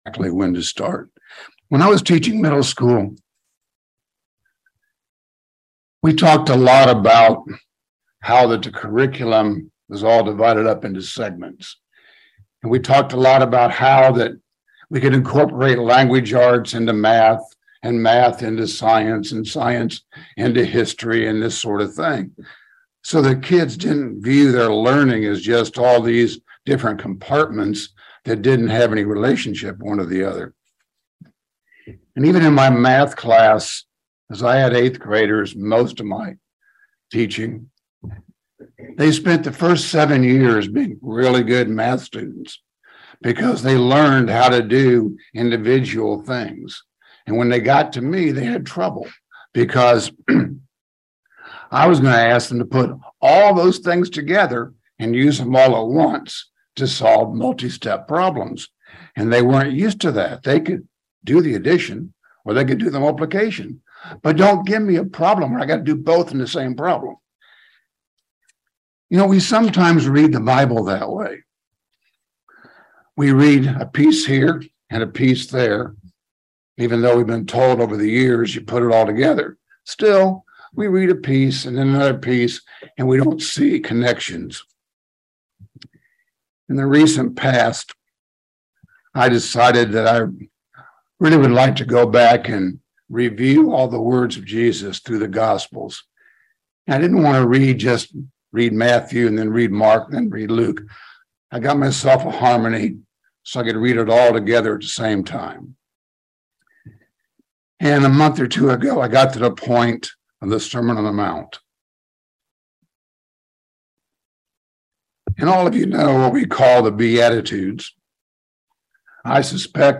Sermons
Given in London, KY